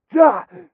m_pain_4.ogg